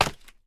sounds / step / scaffold7.ogg
scaffold7.ogg